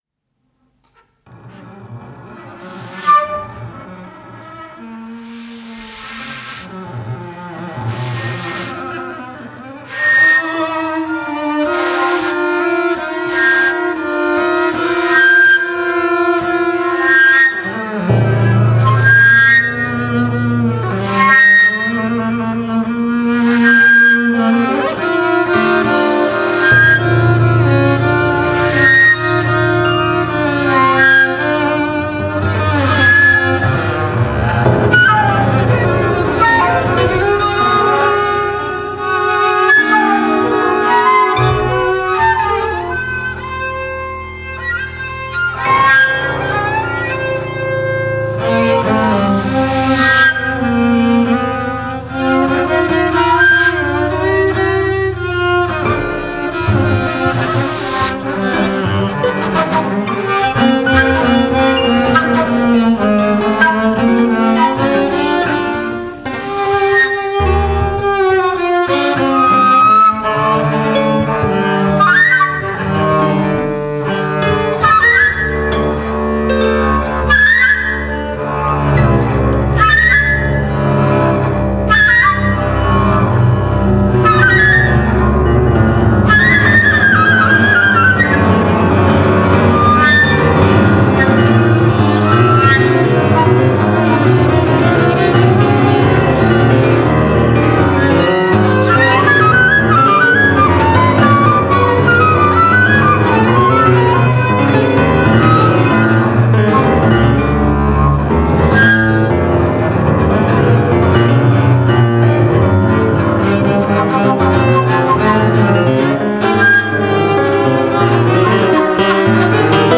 flute
piano
piccolo bass